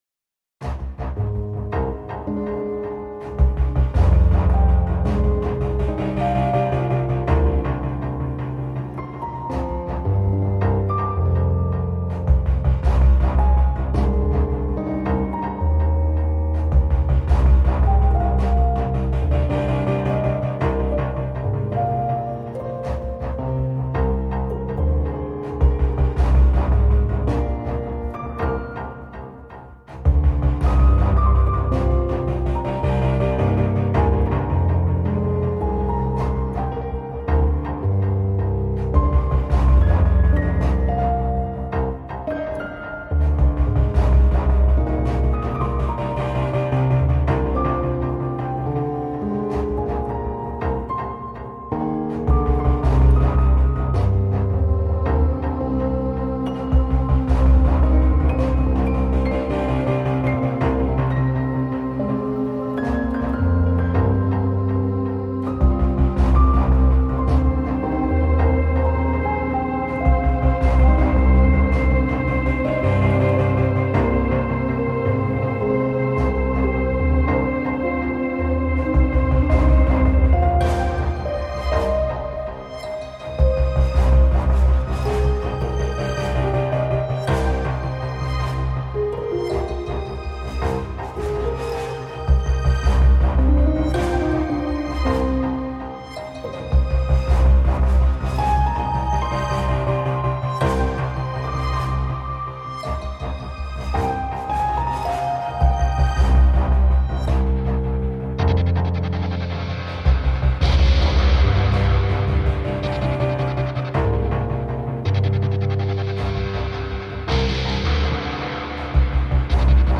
Let the piano speak.